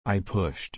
/pʊʃt/